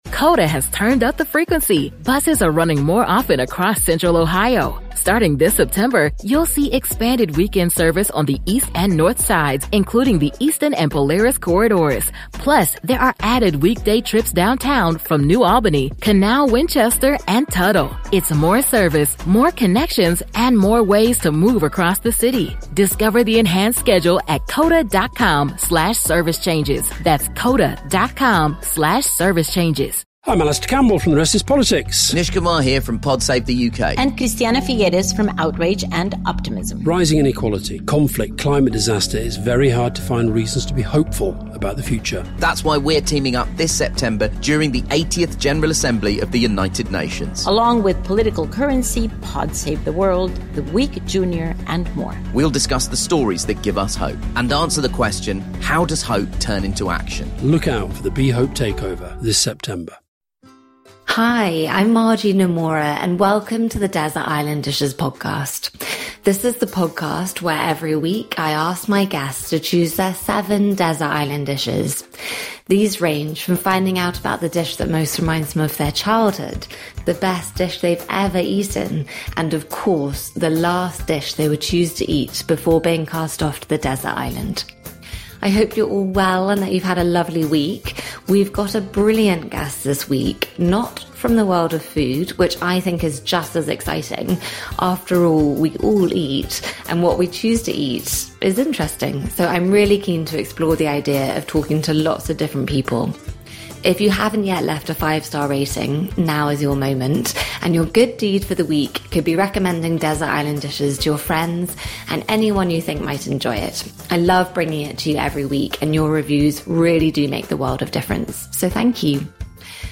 My guest today is Jacqueline Gold